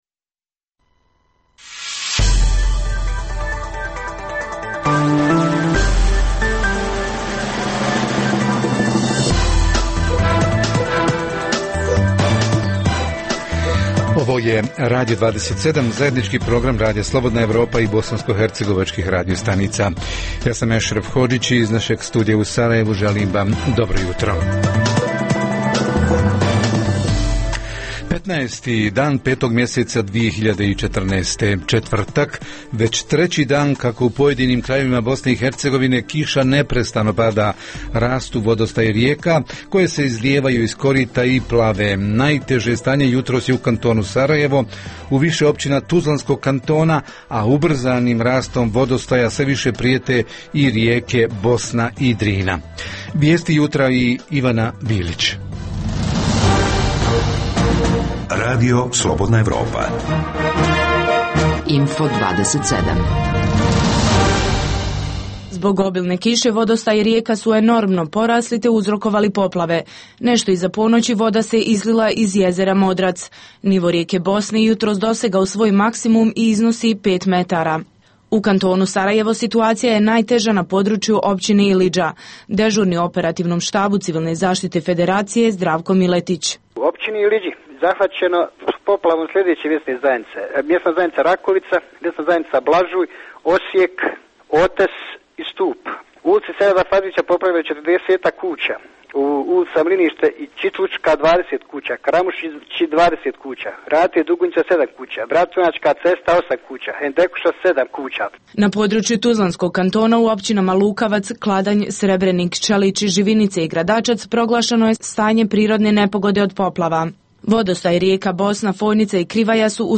Javiće se naši reporteri iz Sarajeva i Višegrada, a pratimo i stanje u općinama Tuzlanskog kantona i u naseljema uz rijeke Bosnu i Drinu. Jutros tražimo i odgovor na pitanja: zašto u Kantonu Sarajevo štrajkove najavljuju policajci i prosvjetni radnici?